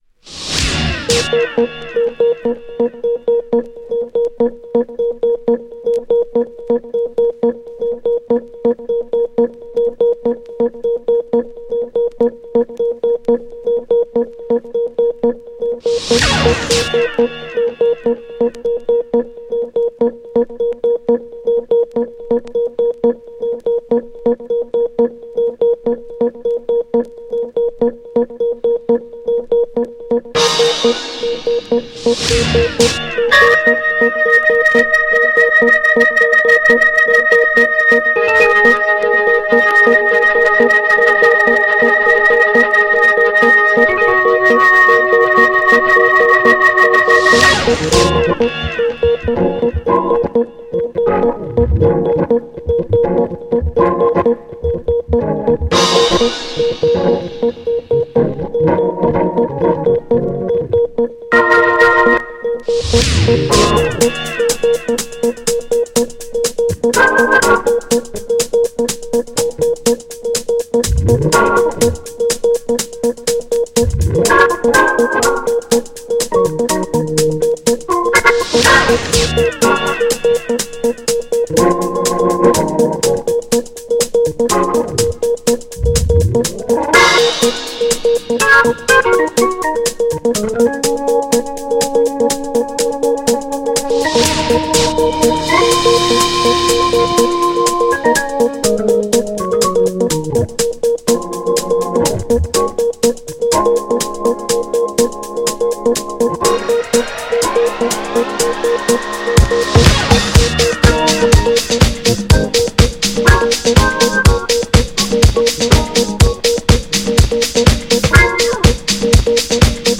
オルガンがフィーチャーされた２種類のHOUSE MIX、全部良いです!!
GENRE House
BPM 121〜125BPM